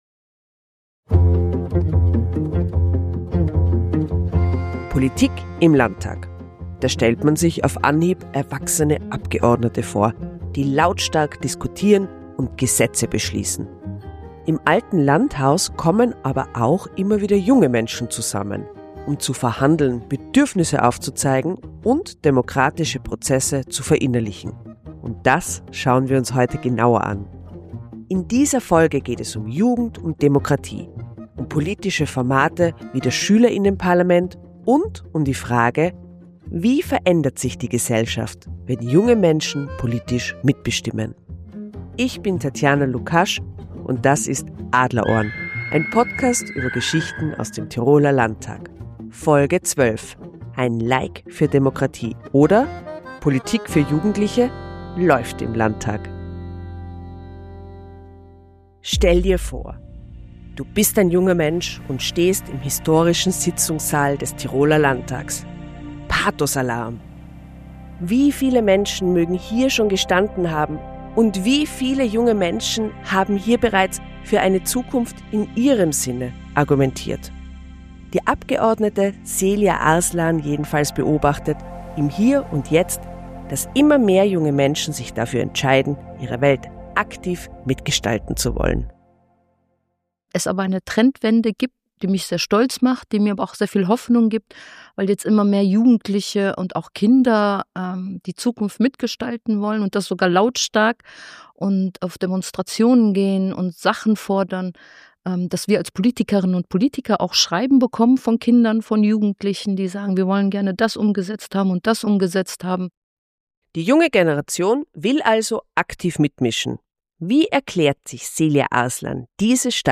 Wir sprechen mit einer Abgeordneten, dem Kinder- und Jugendanwalt, einem Schüler:innenvertreter sowie zwei Teilnehmer:innen der Workshop-Reihe Demokratielandschaft Tirol.